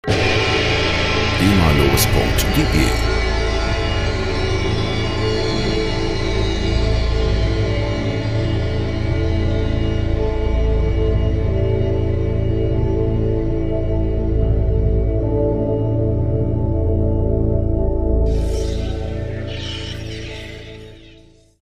freie Musikbetts für Ihre Radiosendung
Musikstil: Ambient
Tempo: 89 bpm